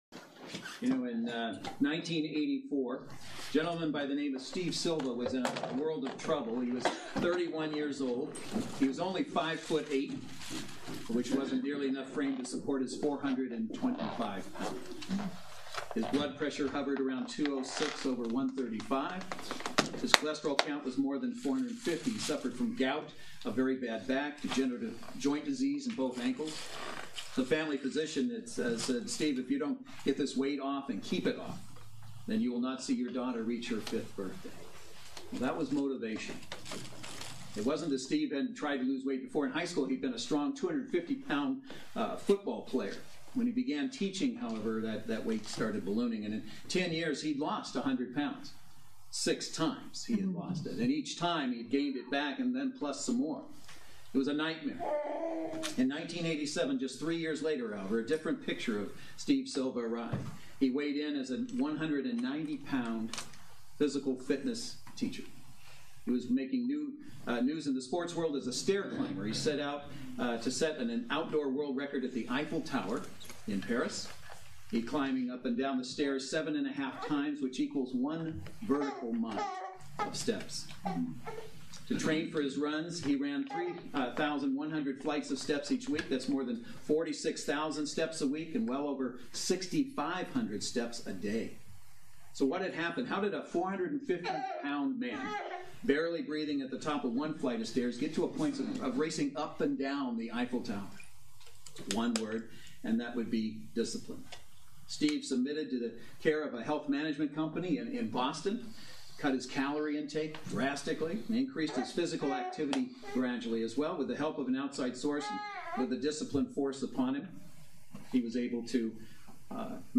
Passage: John 15:1-8 Service Type: Saturday Worship Service